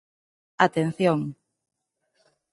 /atenˈθjoŋ/